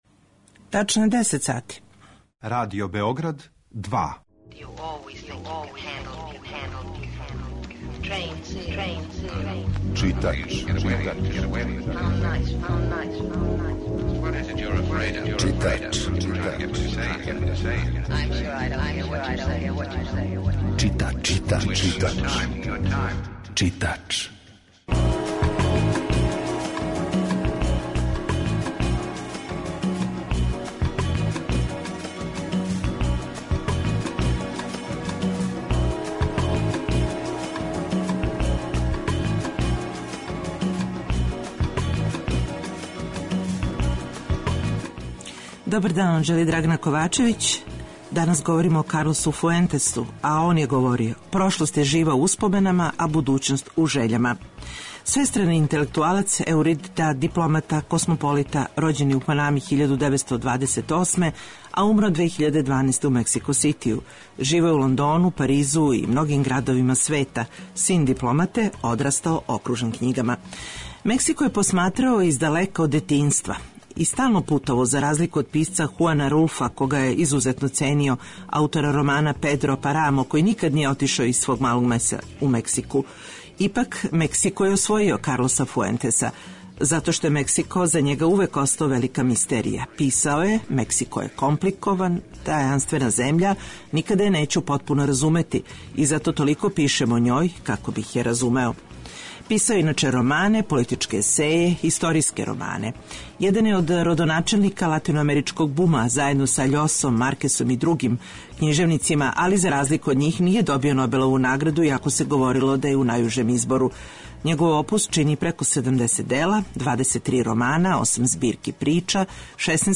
Емисија је колажног типа